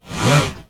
ROBOTIC_Movement_01_mono.wav